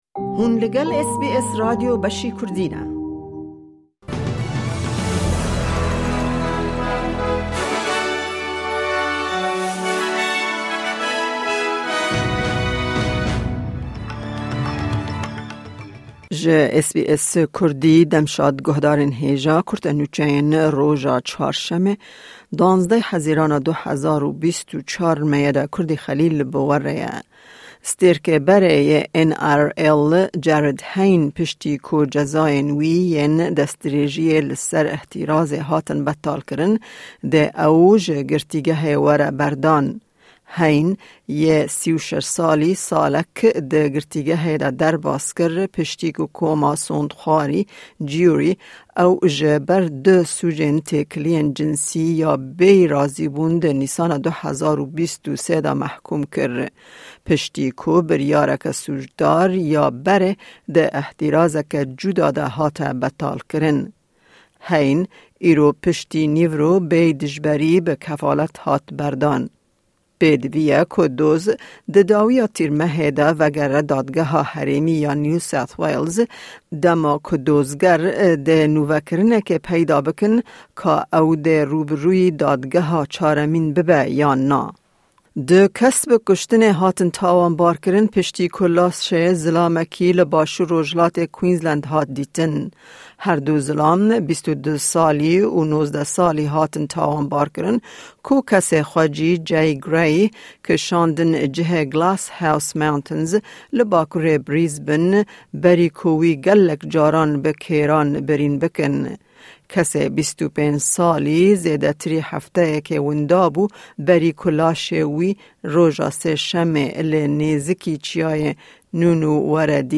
Di bûletena nûçeyan ya îro de: Mehkûmkirina tecawizê ya stêrkê berê yê N-R-L Jarryd Hayne hat betalkirin… Du zilam bi kuştina zilamekî di êrîşa bi kêrê ya 'hovane' li Queensland hatin tawankirin, ew nûçeyana û nûçeyên din di bûletenê de hene.